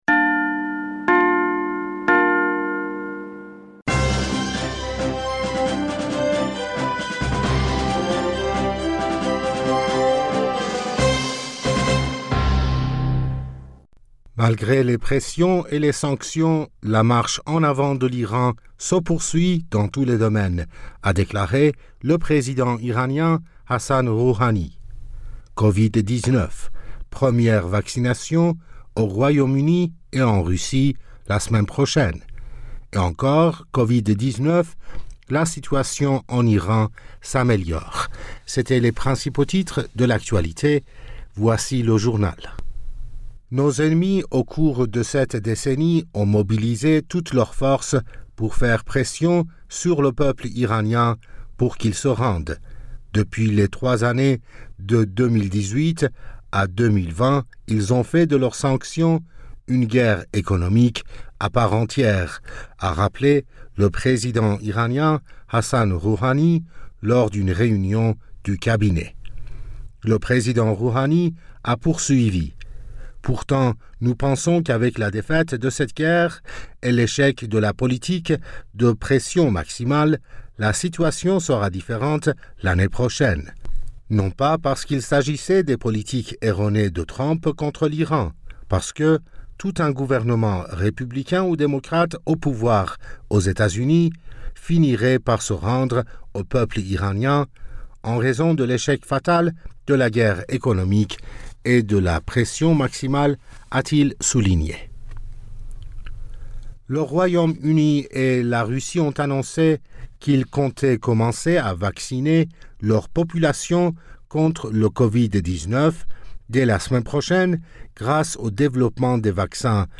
Bulletin d'informationd u 03 Décembre 2020